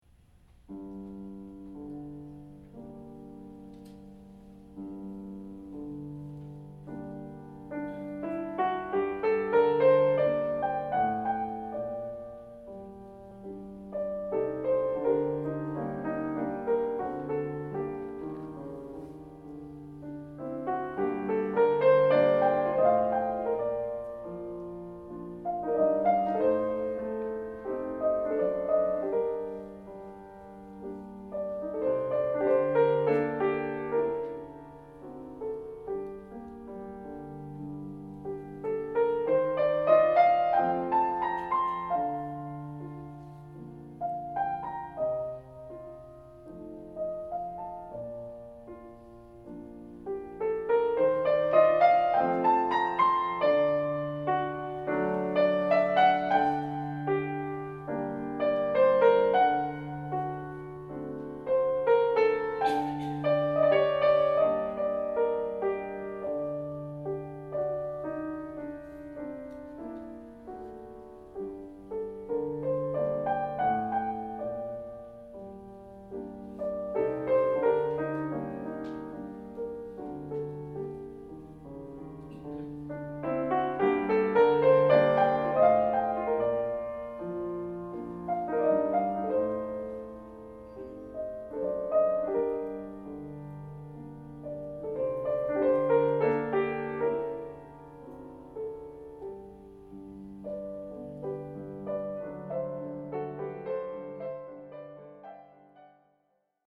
Tchaikovsky Barcarolle excerpt from concert January 24, 2017: